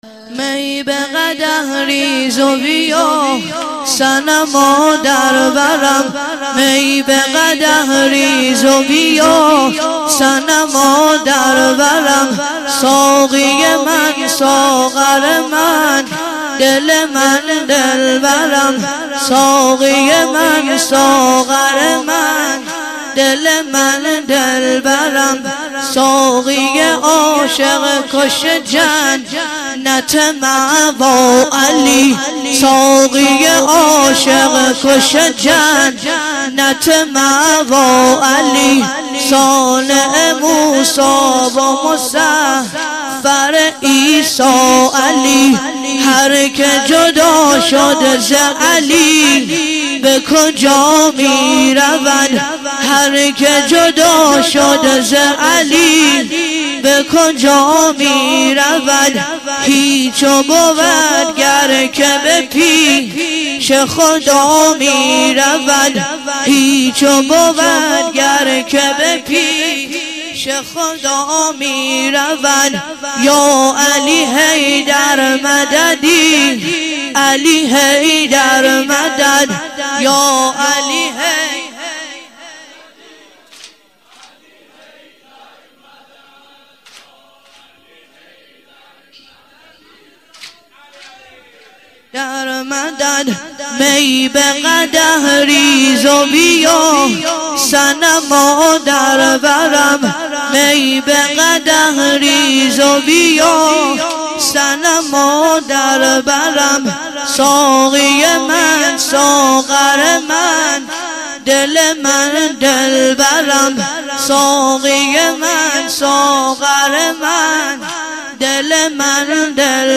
واحد - می به قدح ریز بیار - مداح